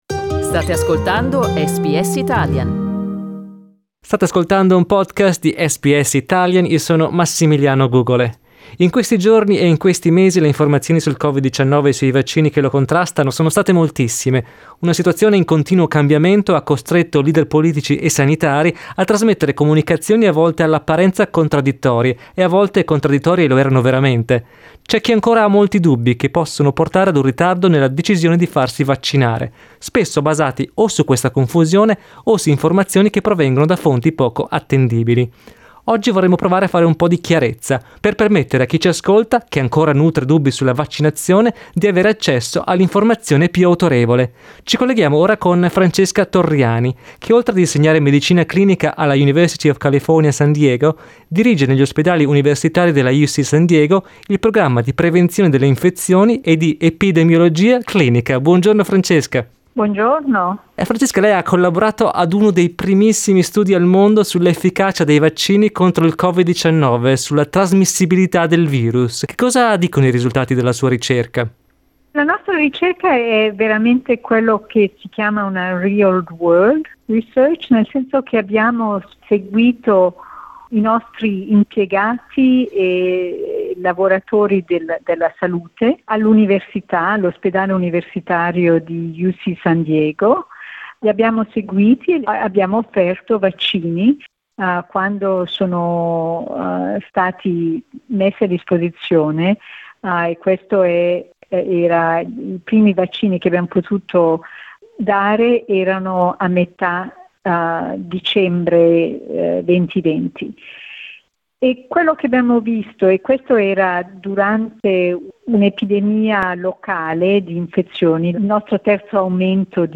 Ascolta la prima parte dell'intervista